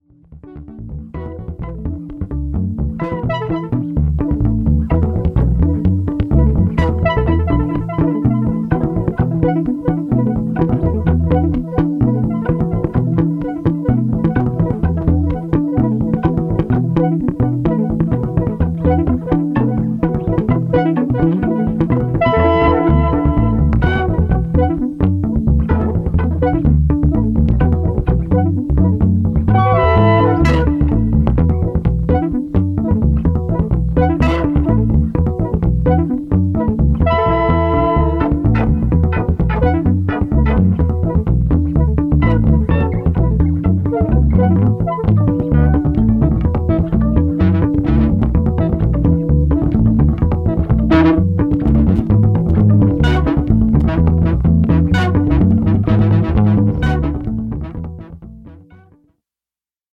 ホーム ｜ JAZZ